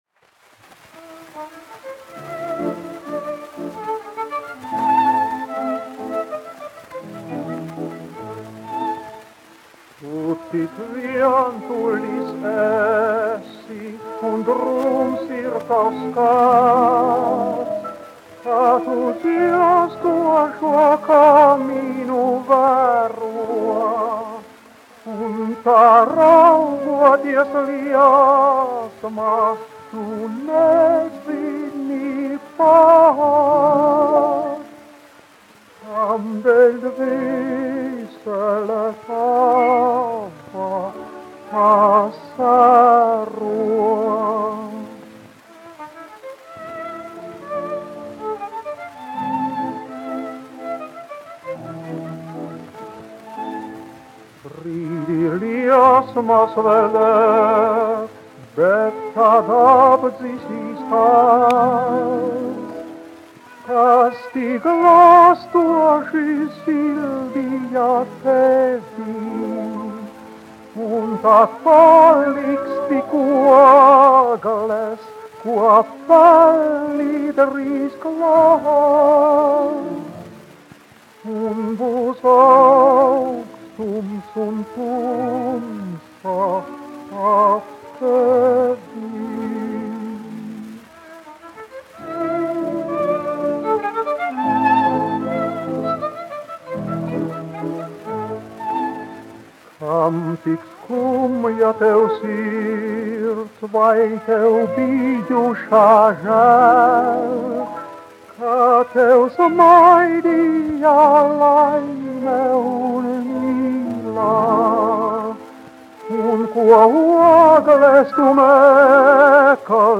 1 skpl. : analogs, 78 apgr/min, mono ; 25 cm
Dziesmas (augsta balss) ar orķestri
Skaņuplate